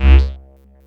PULSBASSC2.wav